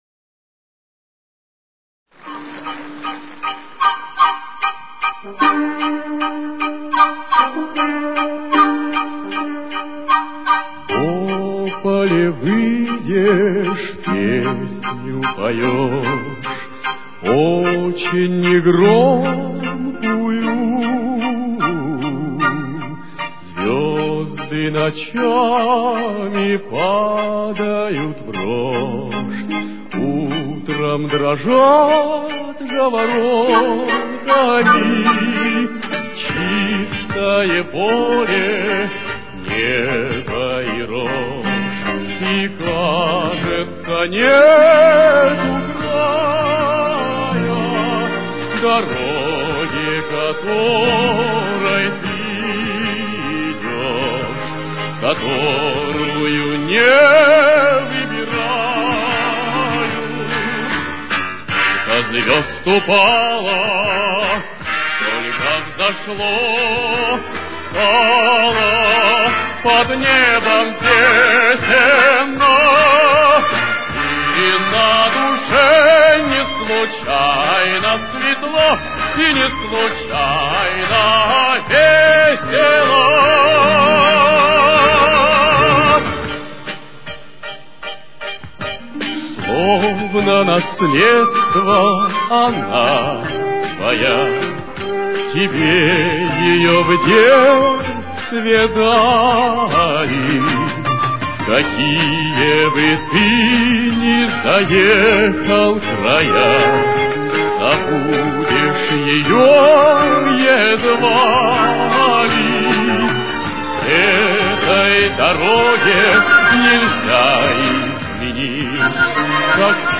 Темп: 159.